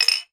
Звуки пива
Банку пива поставили на стол